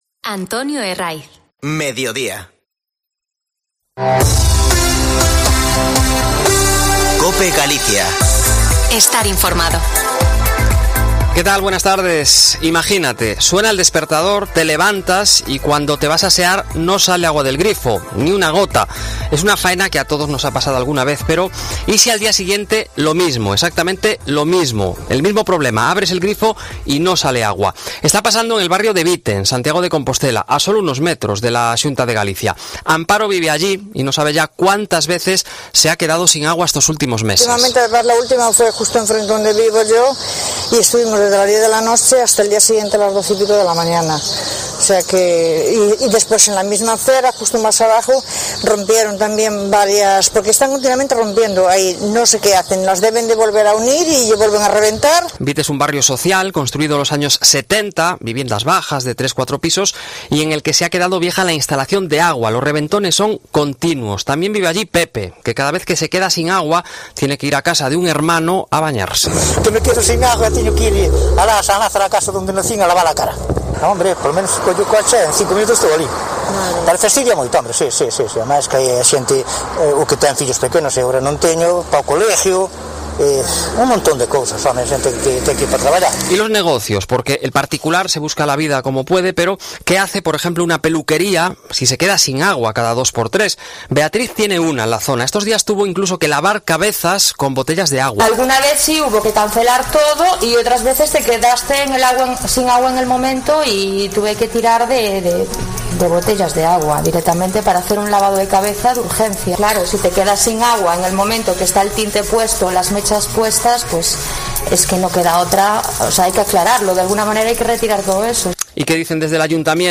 Informativo Mediodia en Cope Galicia 15/06/2021. De 14.48 a 14.58h